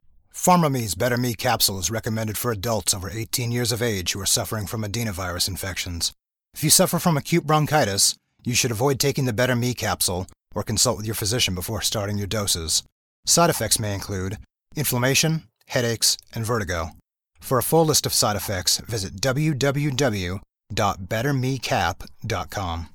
Male
Radio Commercials
Words that describe my voice are Warm, natural, enaging.